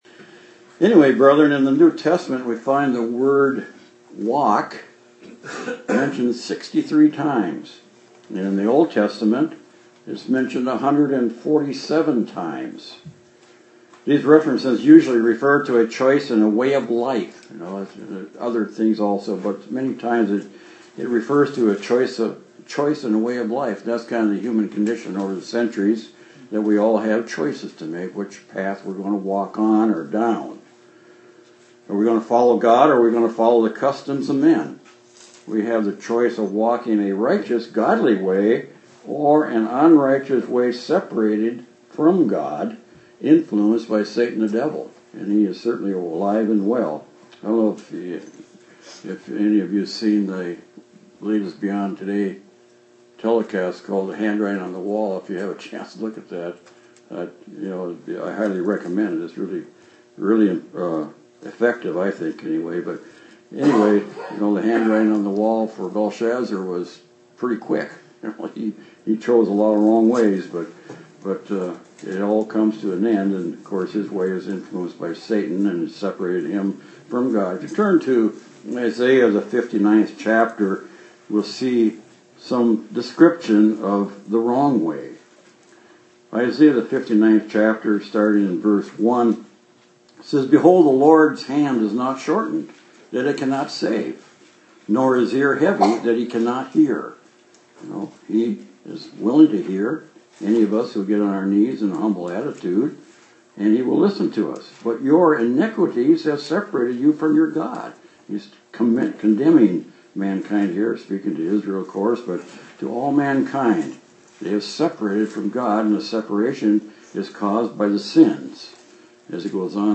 Given in Austin, TX
UCG Sermon Studying the bible?